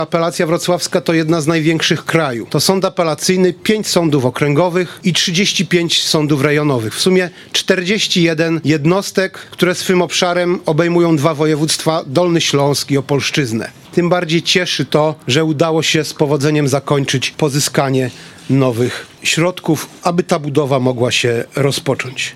Przy ulicy Zielińskiego odbyło się wmurowanie aktu erekcyjnego pod budowę nowego gmachu Sądu Apelacyjnego we Wrocławiu.
„Cieszy to, że udało się z powodzeniem zakończyć pozyskanie nowych środków, aby ta budowa mogła się rozpocząć” – mówił Jacek Saramaga, Prezes Sądu Apelacyjnego we Wrocławiu.